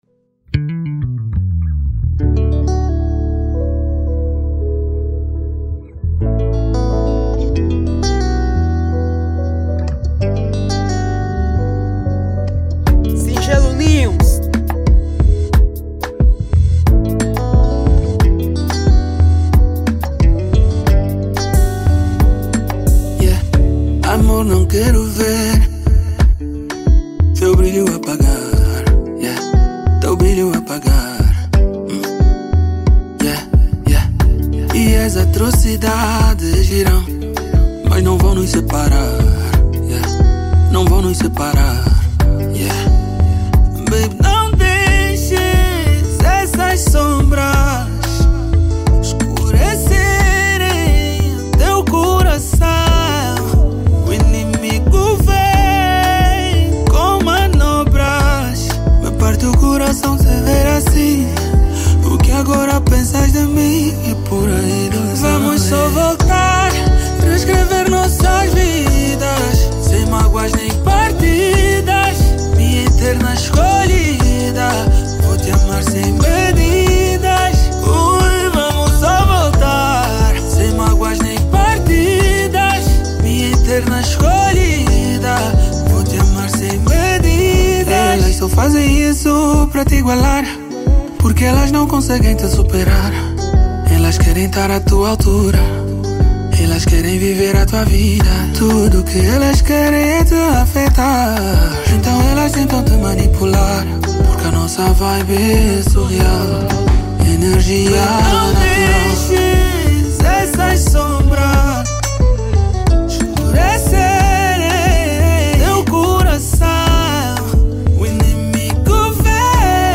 | Zouk